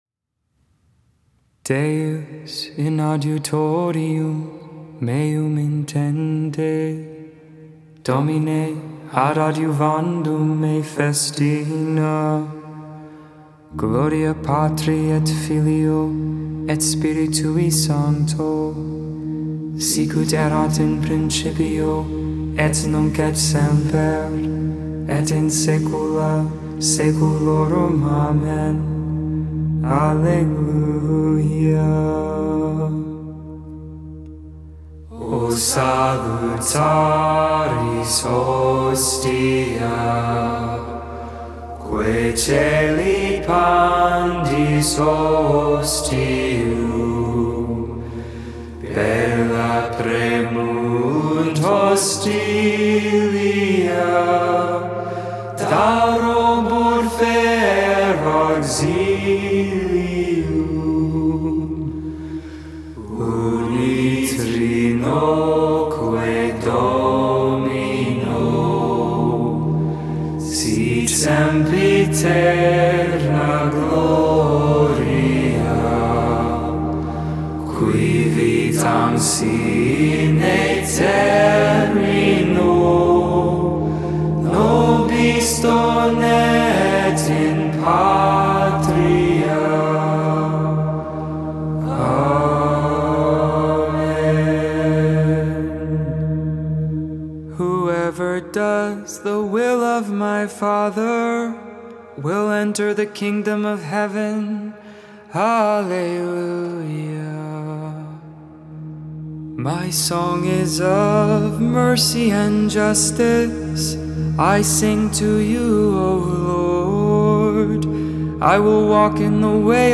Lauds, Morning Prayer for the 4th Tuesday of Eastertide, May 10th, 2022.